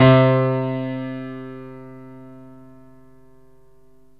PIANO 0013.wav